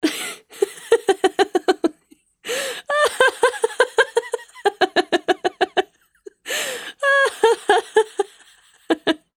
Adult-Fem_Laughter_VKT_17865.wav